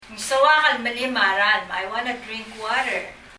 « chéi 海、漁場 farm 農地・農場 » drink 飲む merím [mɛlim] 例） 「私は水を飲みたい」 Ng soak el melím a ralm.